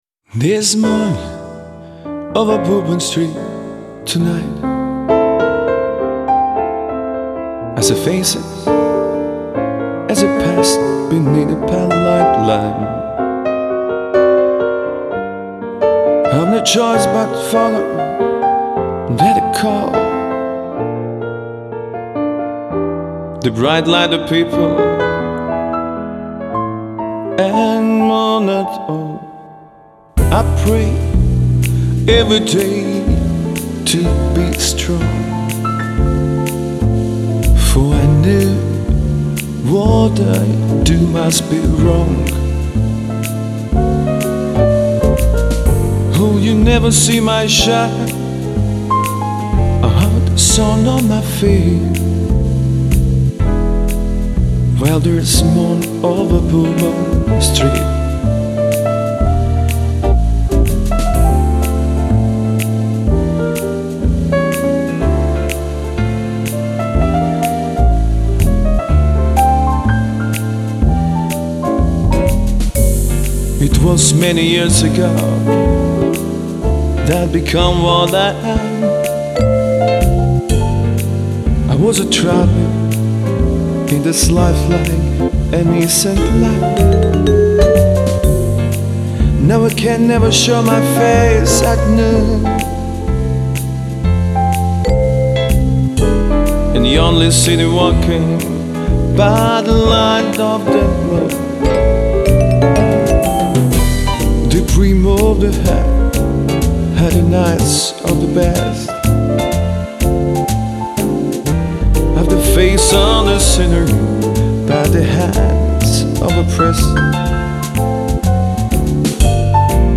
erstklassiger Pianist für Ihre Veranstaltung